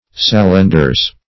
Sallenders \Sal"len*ders\, n. pl. [F. solandres, solandre.]